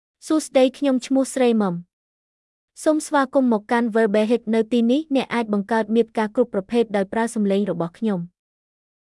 FemaleKhmer (Cambodia)
Voice sample
Female